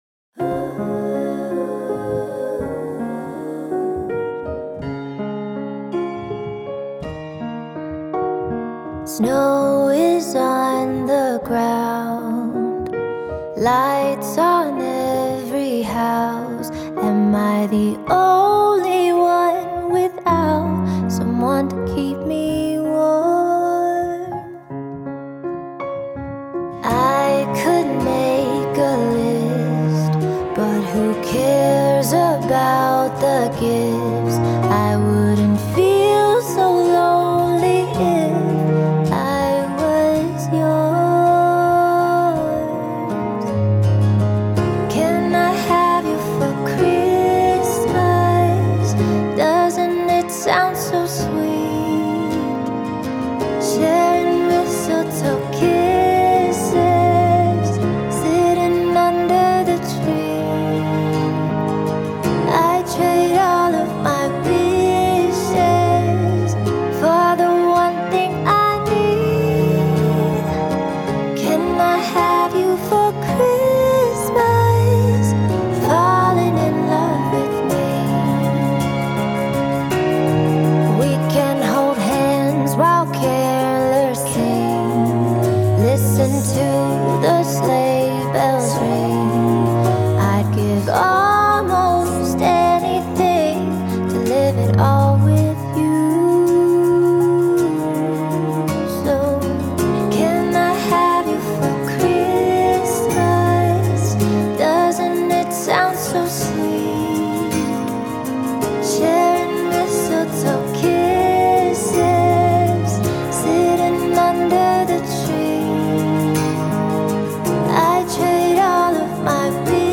Genre: Holiday, folk, pop, singer/songwriter
Tags: Christmas music, dreamy, female vocalist, piano